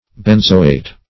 Search Result for " benzoate" : Wordnet 3.0 NOUN (1) 1. any salt or ester of benzoic acid ; The Collaborative International Dictionary of English v.0.48: Benzoate \Ben"zo*ate\, n. [Cf. F. benzoate.]